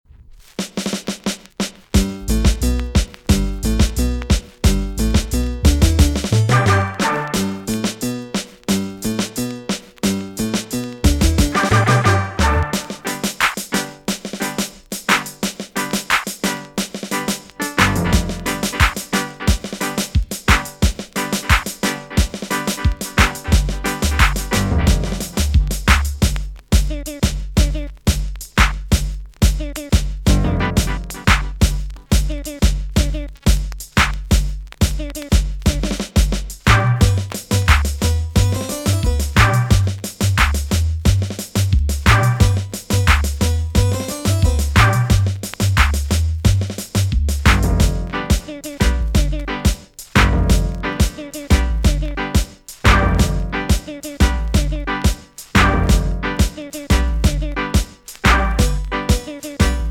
TOP >80'S 90'S DANCEHALL
B.SIDE Version
EX- 音はキレイです。